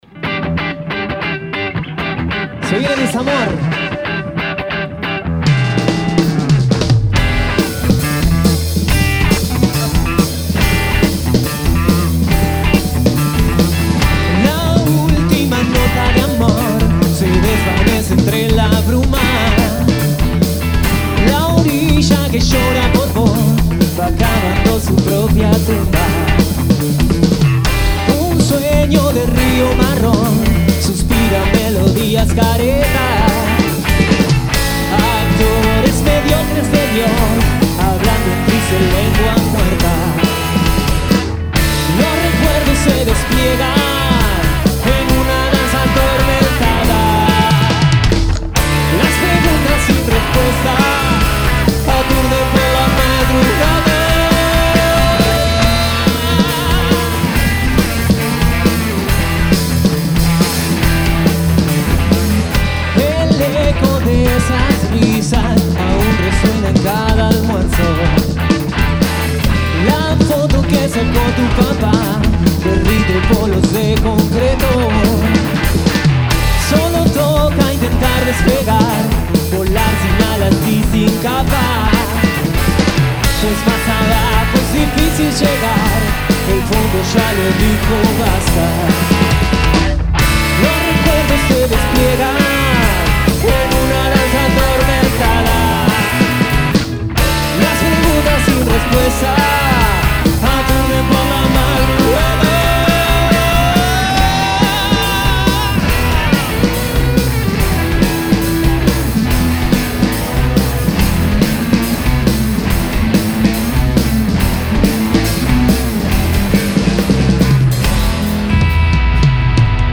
Grabada en vivo el 1 de junio de 2025